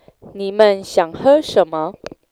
Click to hear sounds.
lesson5-Waiter6.wav